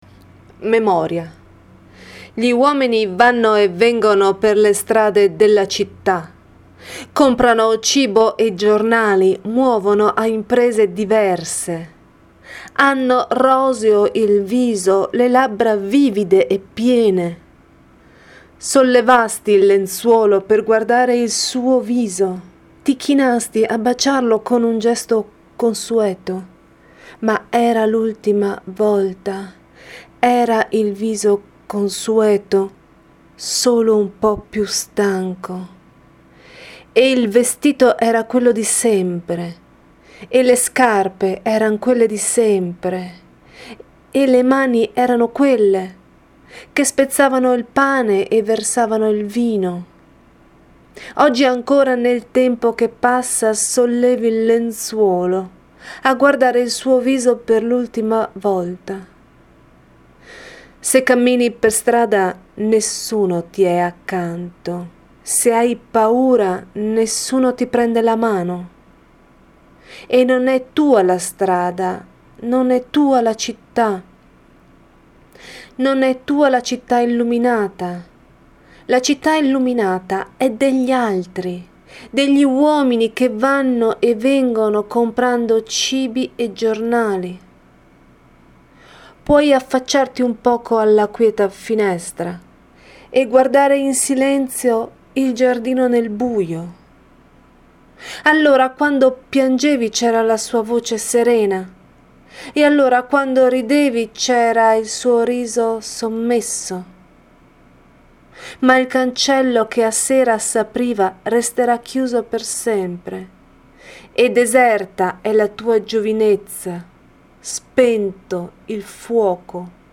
“Memoria” di Natalia Ginzburg letta